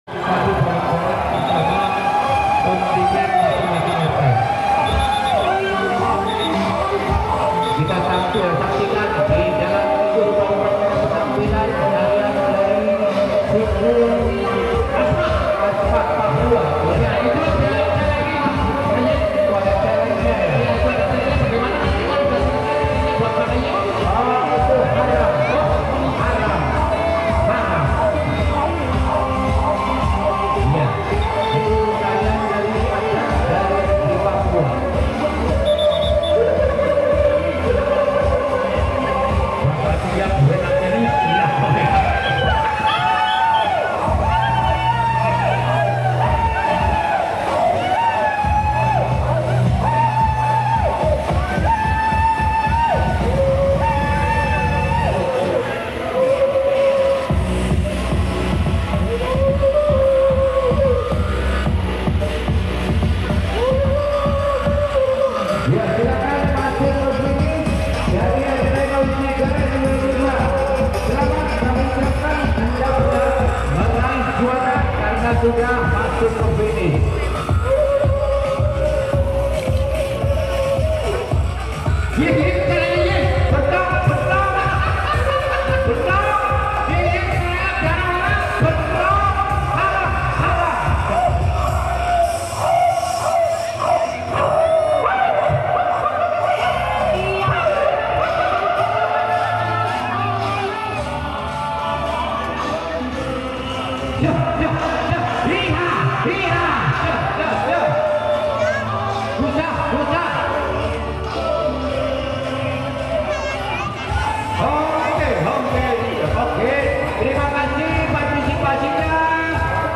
Yamko Rambe Yamko Karnaval Rt.03